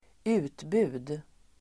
Uttal: [²'u:tbu:d]